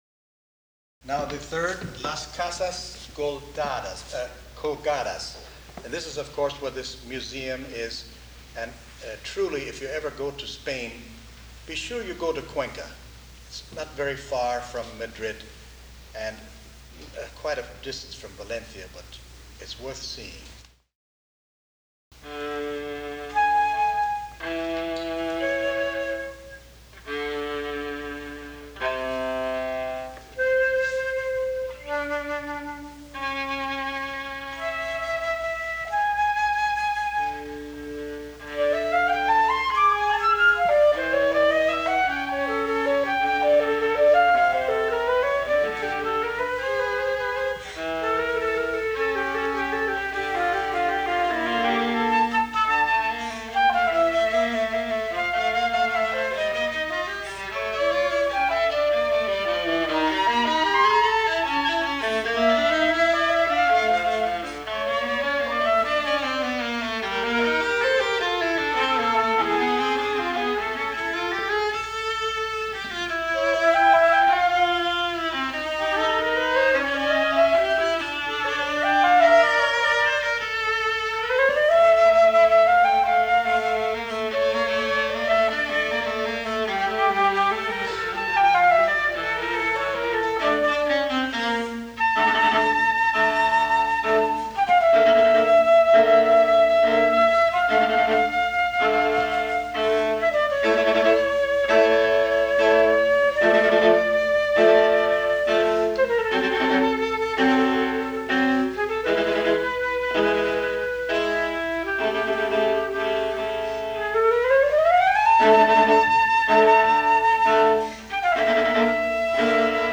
for Flute and Viola
viola
flute